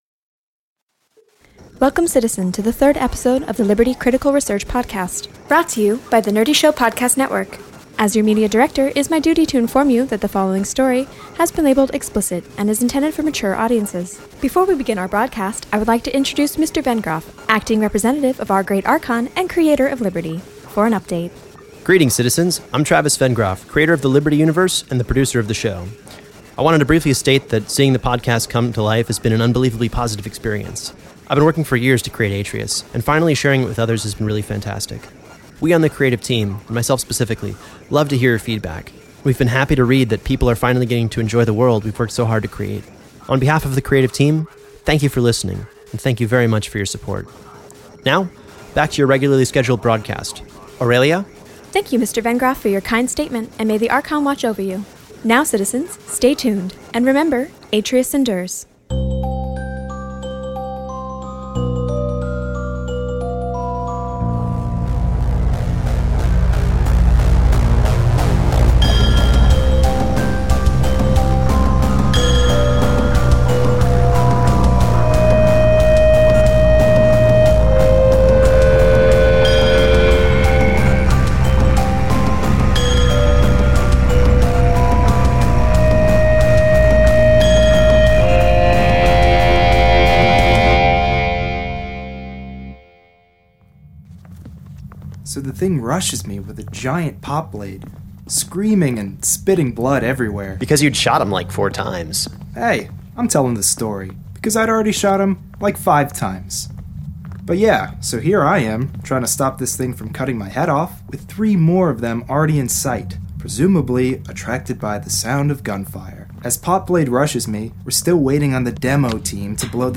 Embark on a dangerous expedition into the unknown in Liberty: Critical Research – a serialized sci-fi audio drama! Follow Dr. Kovski’s team into the Fringe, the lawless sector of a war-torn space colony.